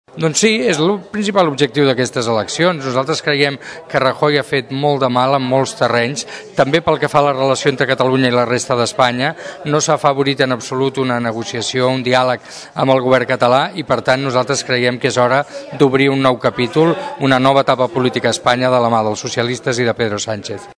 Prèviament, en declaracions en aquesta emissora, Iceta va demanar el vot pels socialistes assegurant que és l’única manera que hi hagi un canvi.